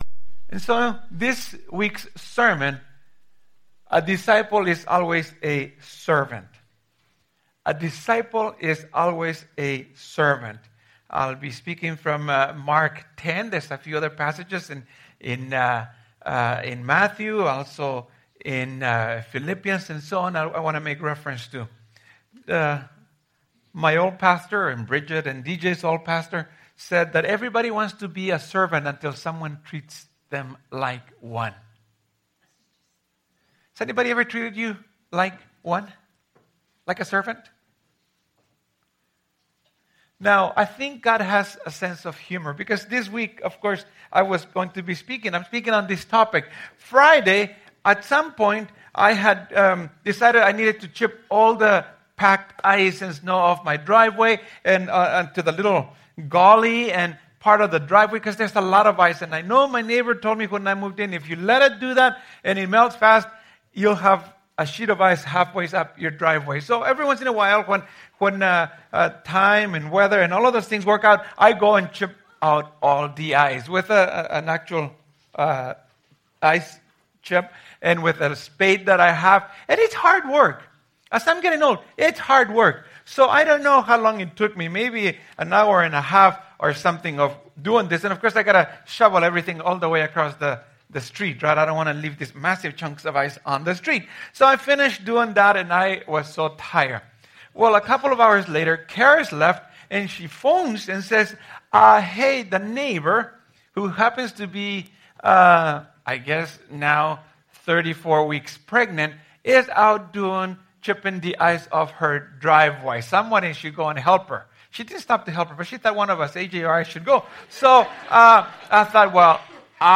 Sermons | Devon Community Church